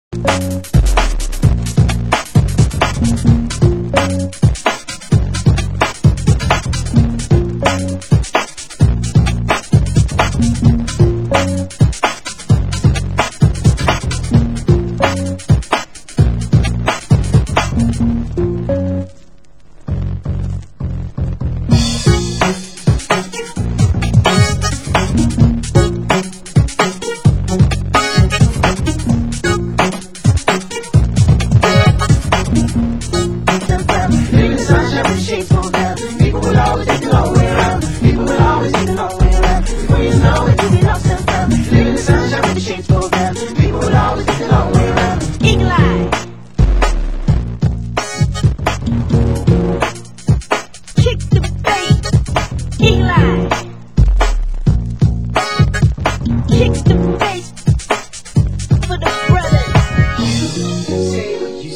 Genre: UK Garage
Vocal Club Mix